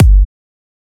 edm-kick-18.wav